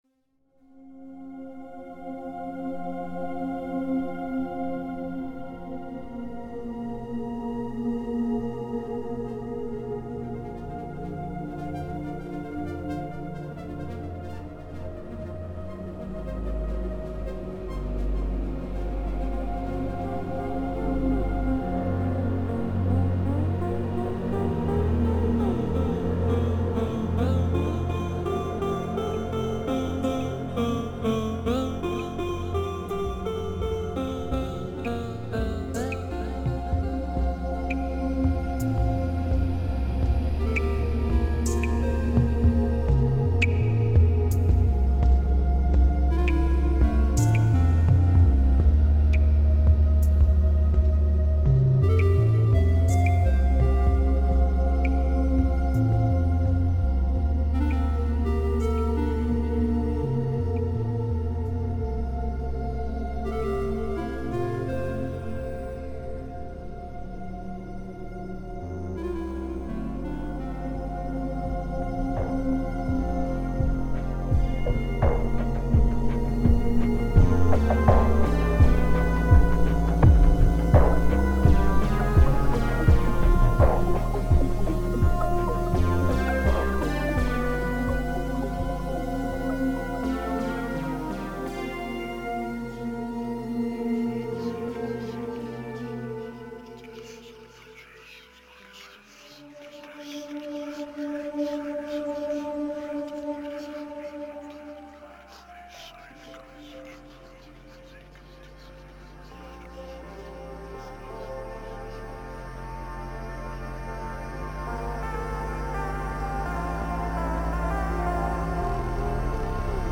this song i made during tilde30. it's a bit messy in places, but i like the idea behind it.